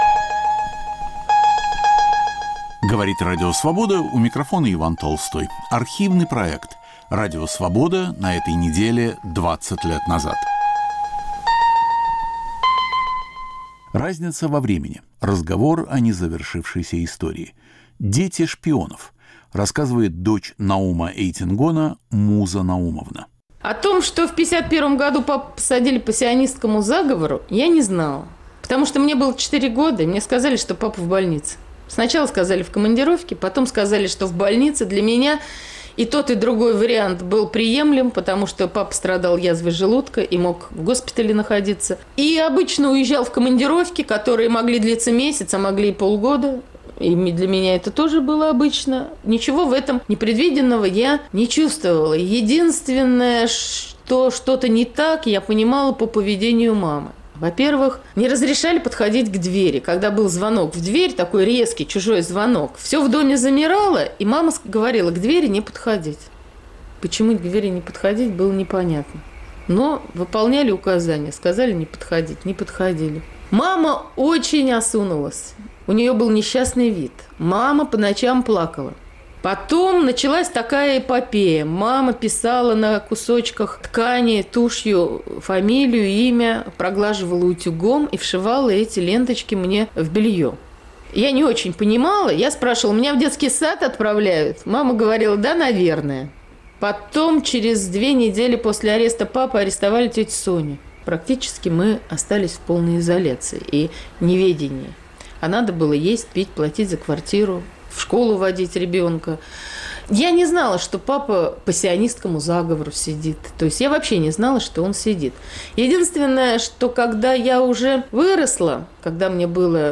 Автор и ведущий Владимир Тольц.